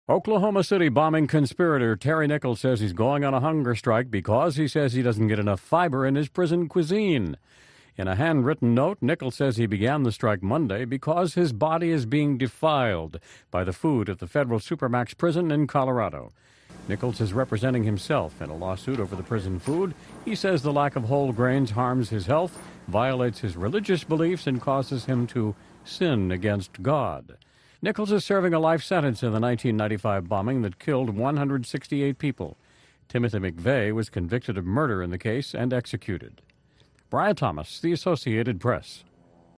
A short news clip covering the Oklahoma City bombing suspect, Terry Nichols, hunger strike.